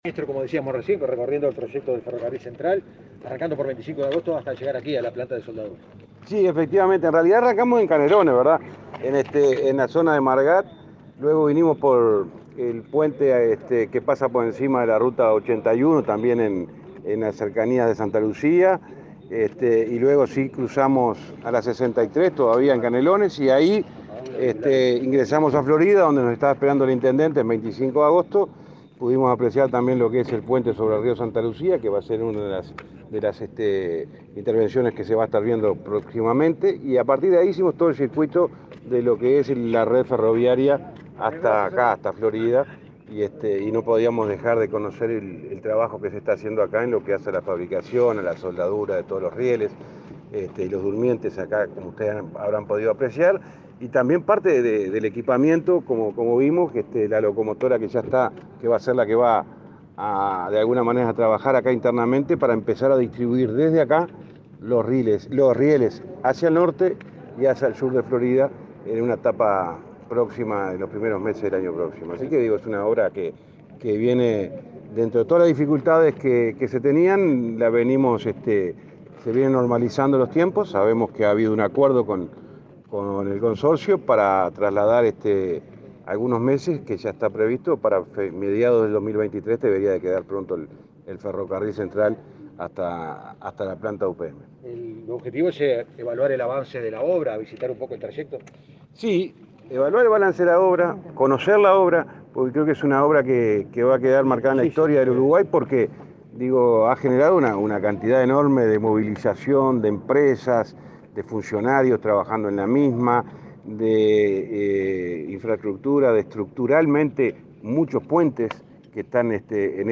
Declaraciones a la prensa del ministro de Transporte, José Luis Falero